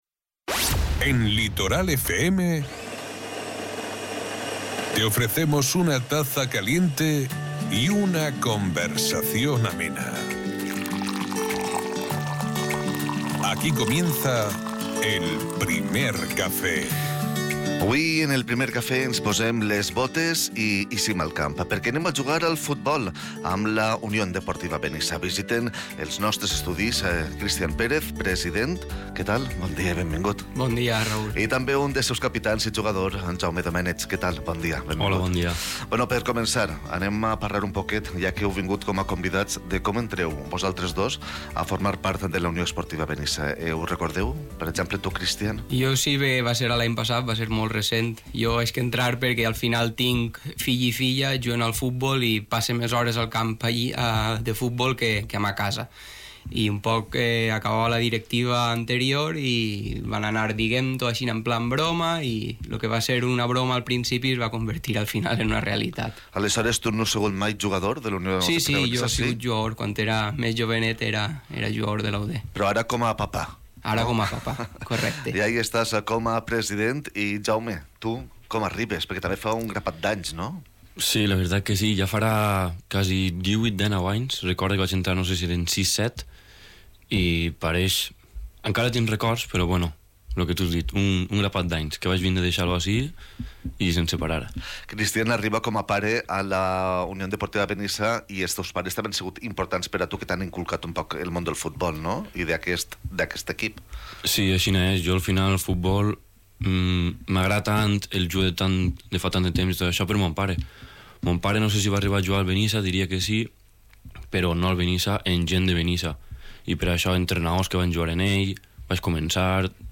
Esta entrevista ha servido también para hacer balance de la campaña anterior y compartir las expectativas para el nuevo curso, en el que el club busca consolidar su proyecto deportivo y mantener el vínculo con la afición como uno de sus pilares fundamentales.